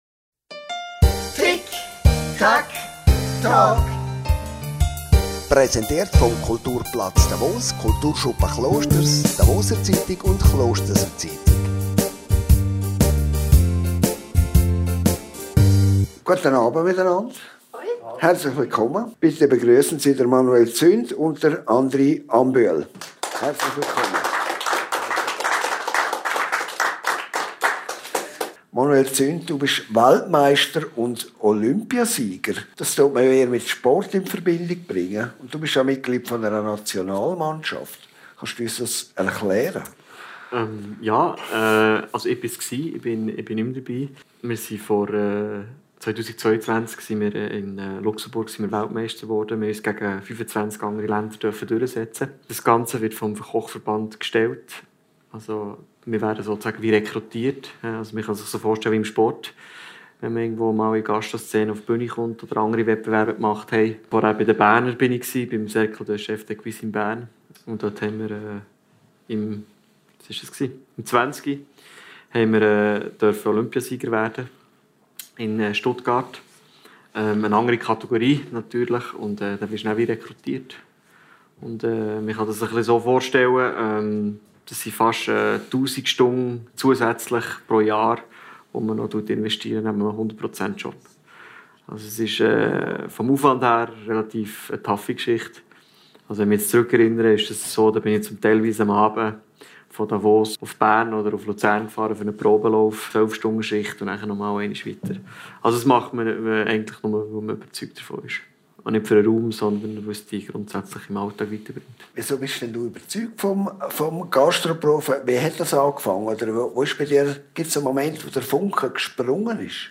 Talkshow und Podcast, präsentiert von der «Klosterser Zeitung», dem Kulturschuppen Klosters, der «Davoser Zeitung» und dem Kulturplatz Davos.